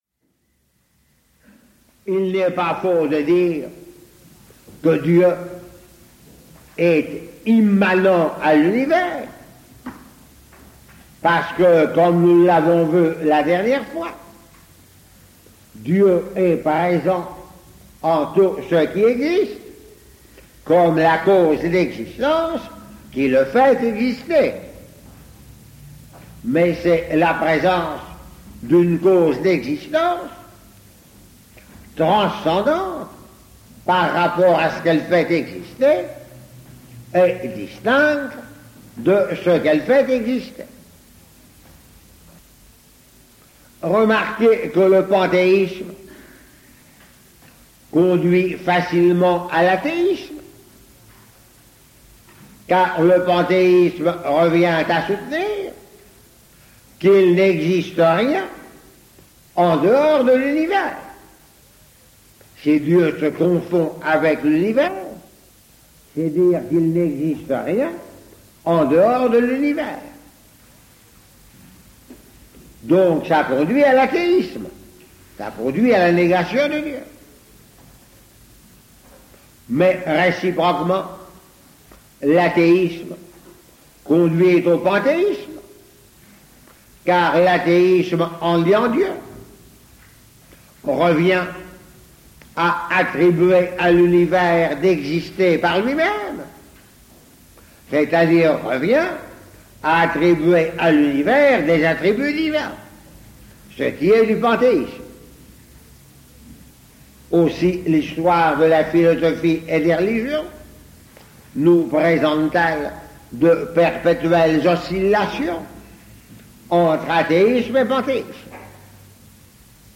Enseignement
Il s'agit de documents d'archive dont la qualité technique est très médiocre, mais dont le contenu est particulièrement intéressant et tout à fait conforme à l'enseignement de l'Eglise Catholique.